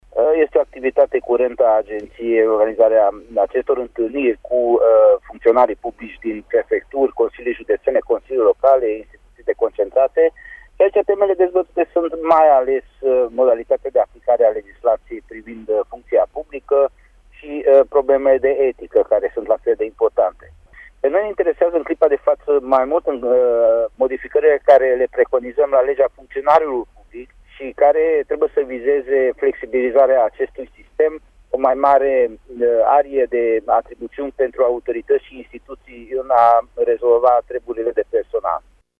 Birtalan Jozsef, preşedintele Agenţiei Naţionale a Funcţionarilor Publici va fi prezent la eveniment: